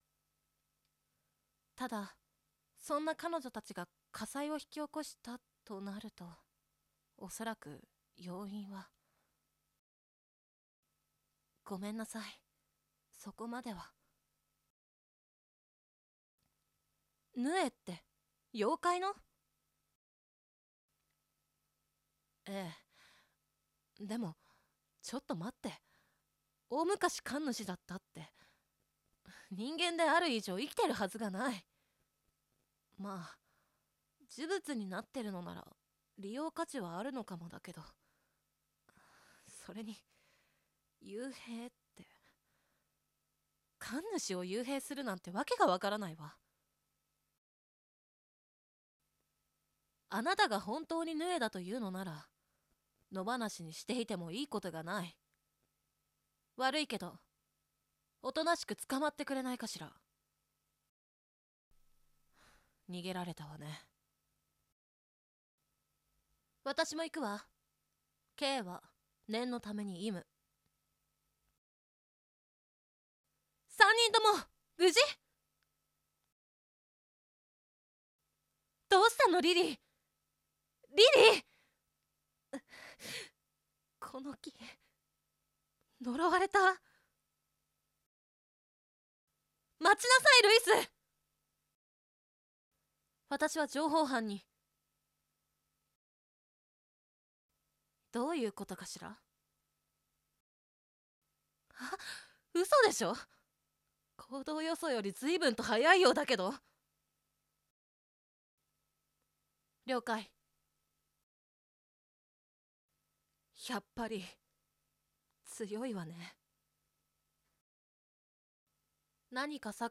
🎑セリフ